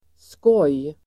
Uttal: [skåj:]